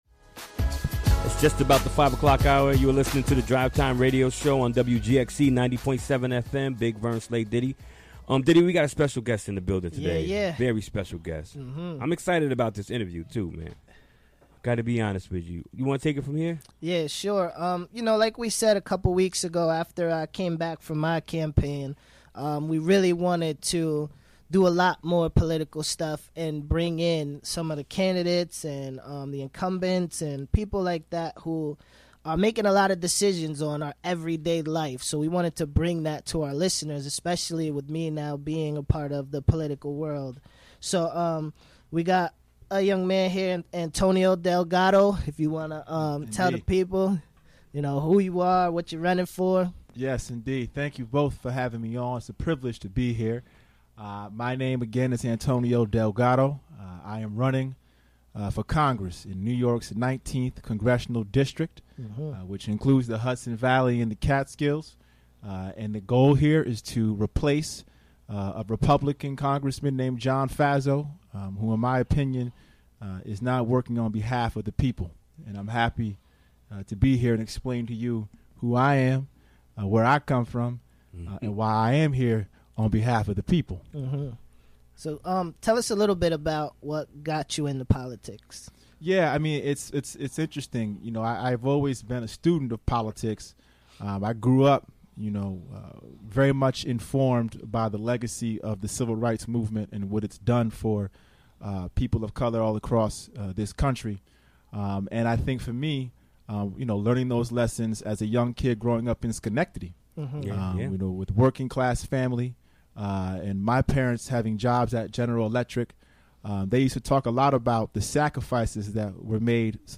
Recorded during the WGXC Afternoon Show Wednesday, November 29, 2017.